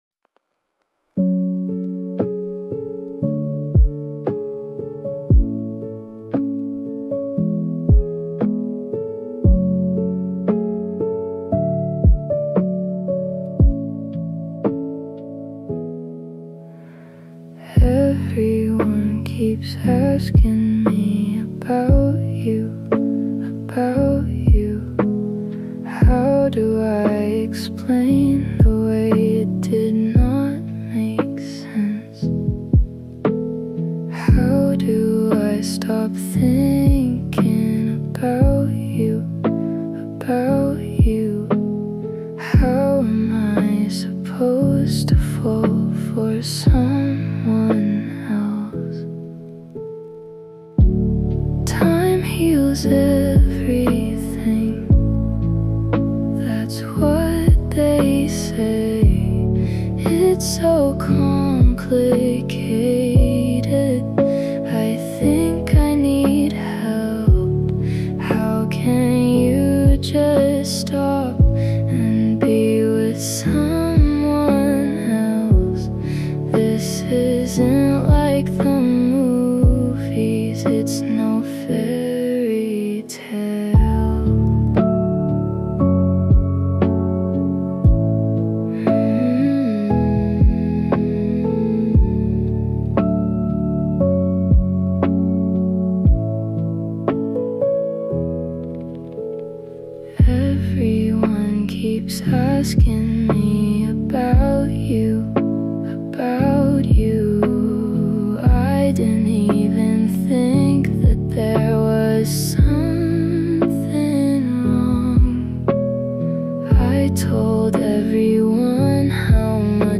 There is a soft charm in how this track begins.